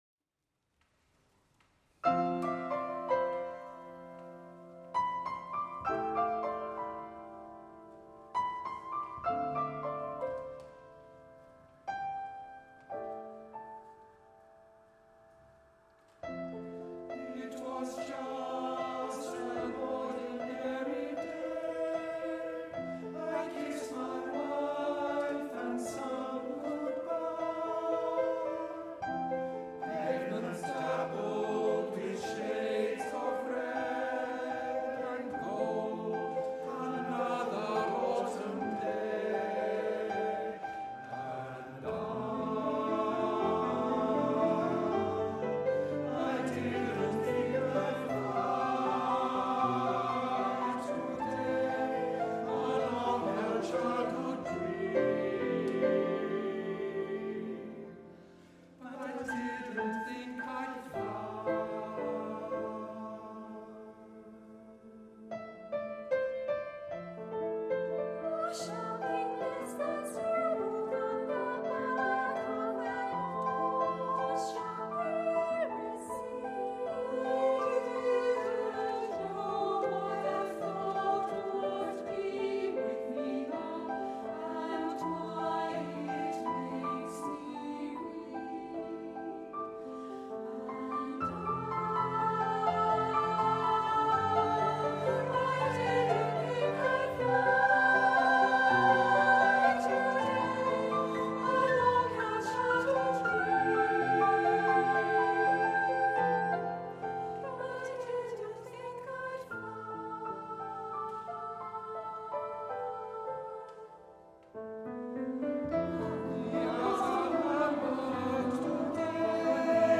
live recording
SSATBarB with piano